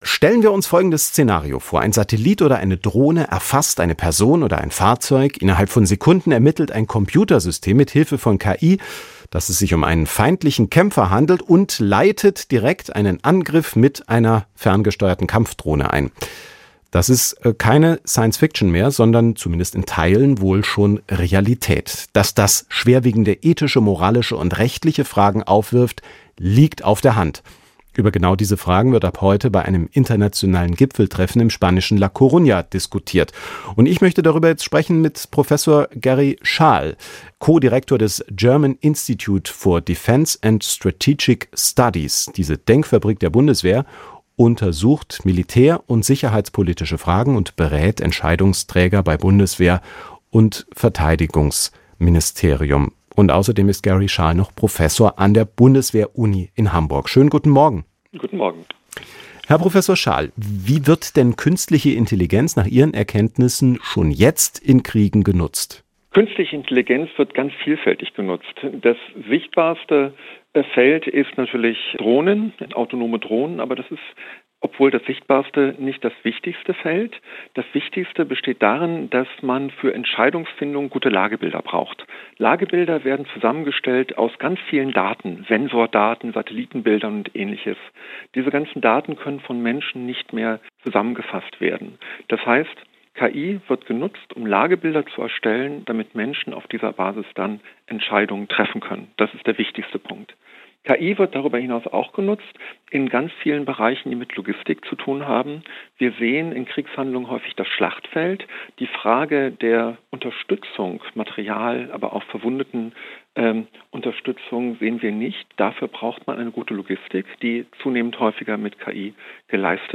Erweiterte Suche KI beim Militär: Wieviel Kontrolle darf der Mensch abgeben? 10 Minuten 9.26 MB Podcast Podcaster SWR Aktuell Im Gespräch Die interessantesten Interviews aus dem Radioprogramm von SWR Aktuell finden Sie hier zum Nachhören.